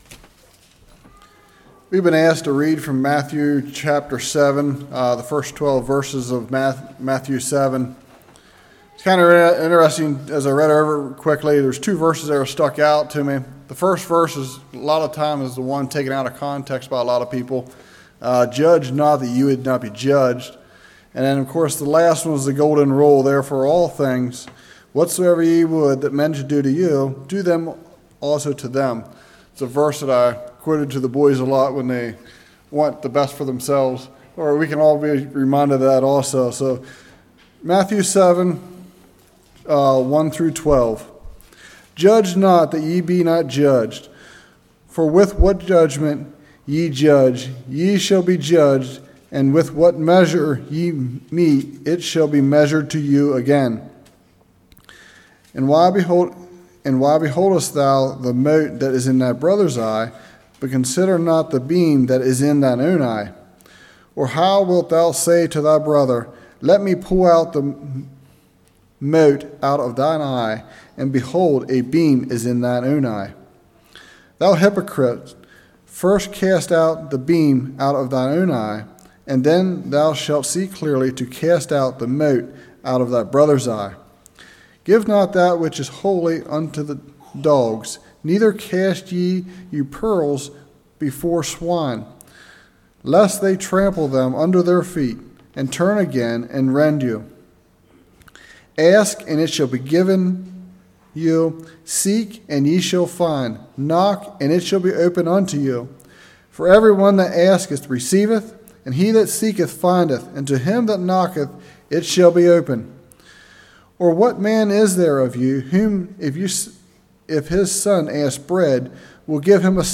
Matthew 7:1-12 Service Type: Evening Judge not